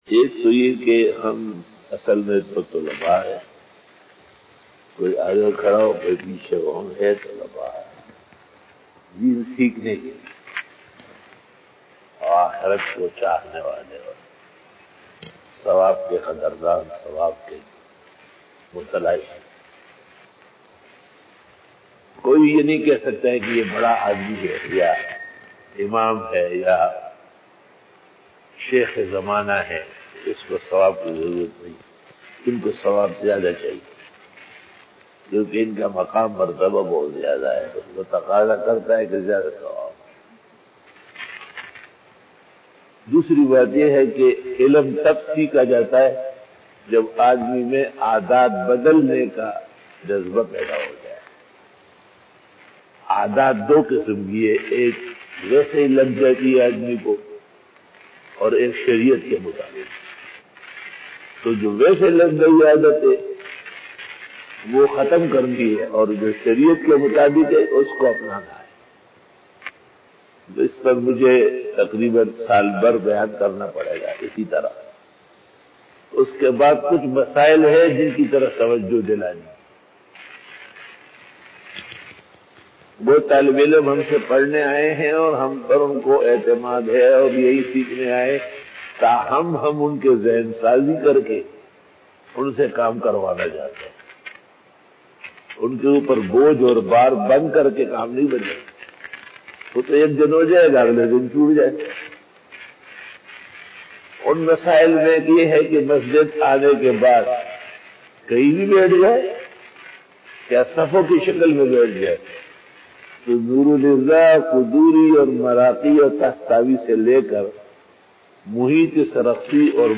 Fajar bayan 06 October 2020 (18 Safar ul Muzaffar 1442HJ) Tuesday
بعد نماز فجر بیان 06 اکتوبر 2020ء بمطابق 18 صفر 1442ھ بروزمنگل